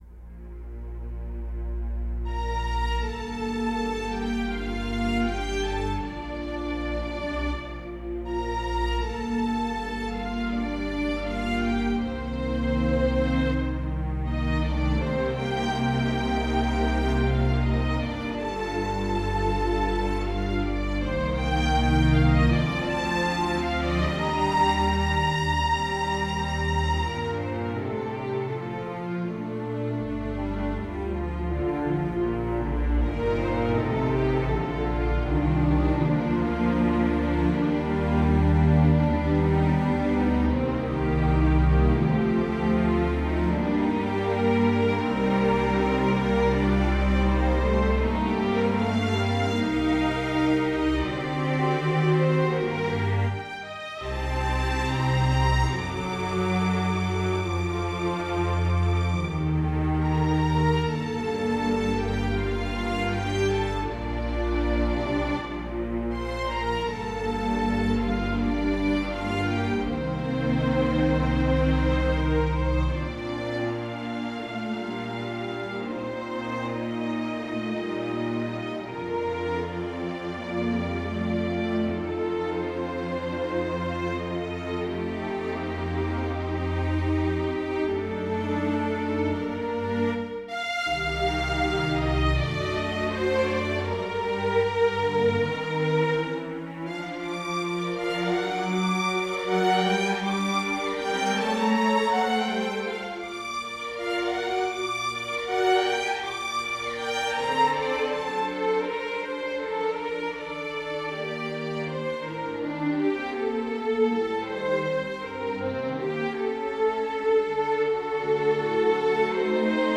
Andante No.2 in G minor - Orchestral and Large Ensemble - Young Composers Music Forum